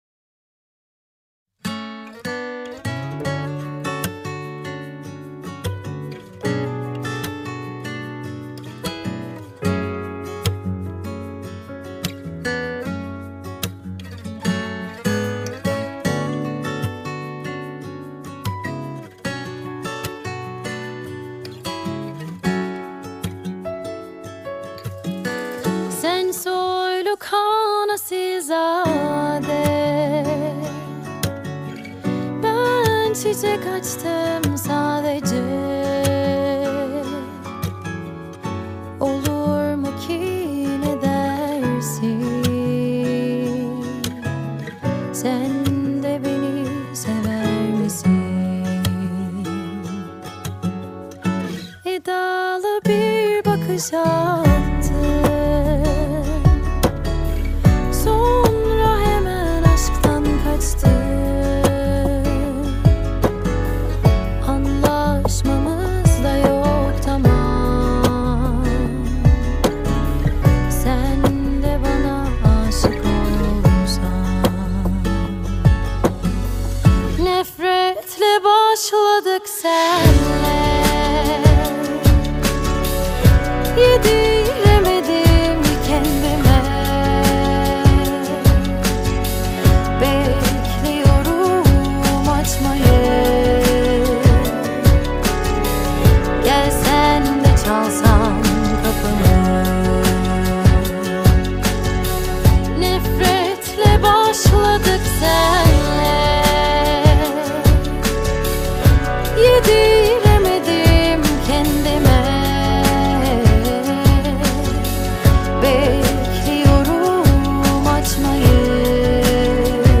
duygusal huzurlu rahatlatıcı şarkı.